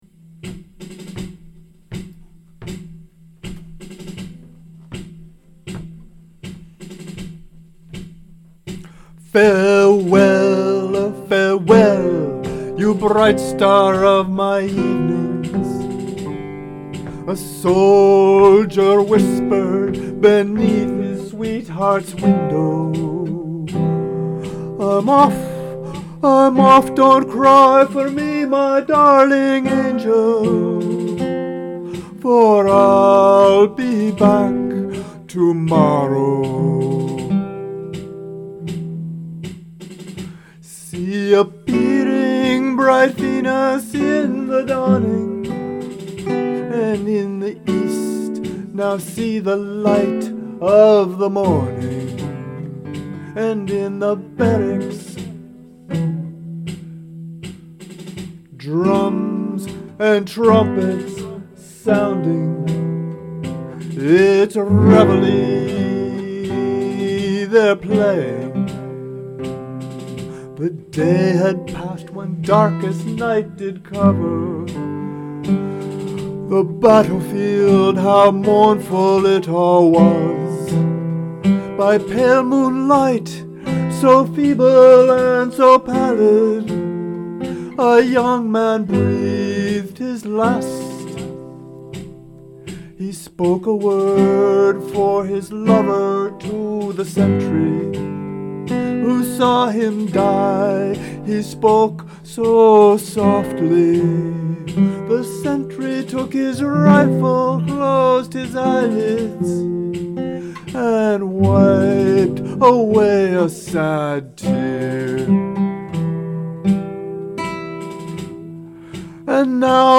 Classical guitar with carbon strings, and somewhat cringy vocals. Slow tango beat from BeatBuddy. Slightly mastered using GarageBand.